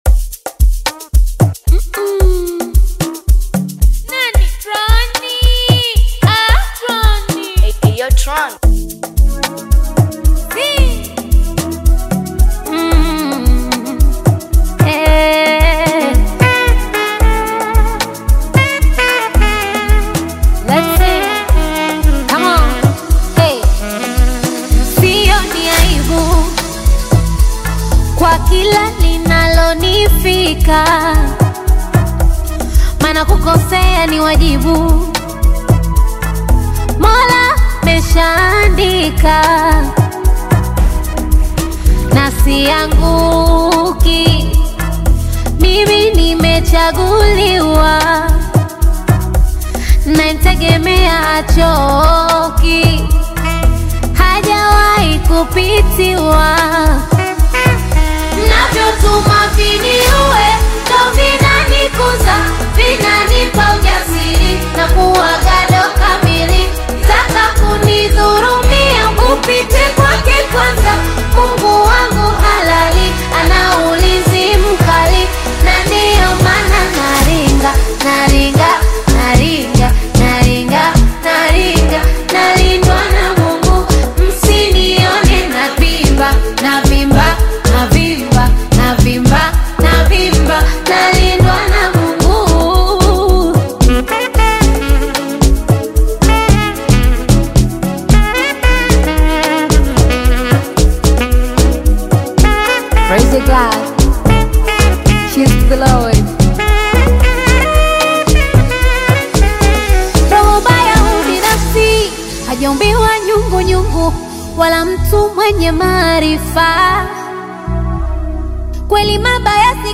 a brand new Amapiano song